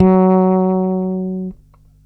32-F#3.wav